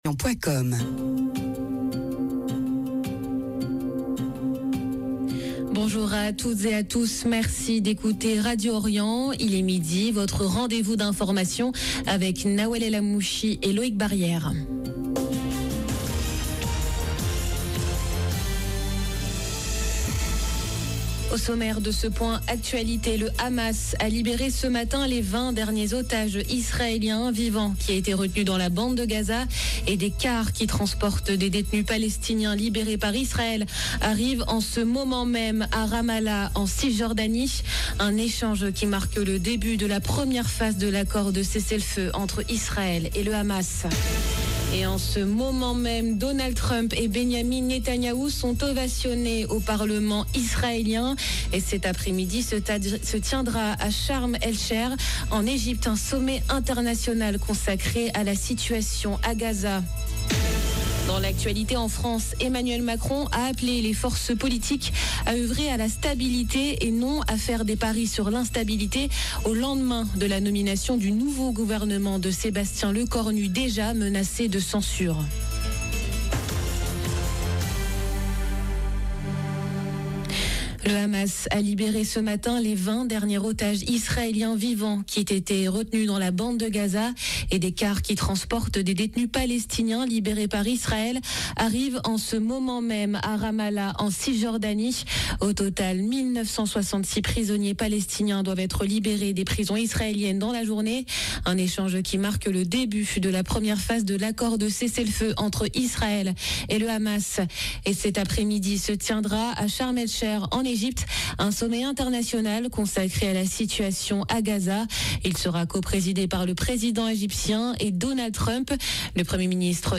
JOURANL DE MIDI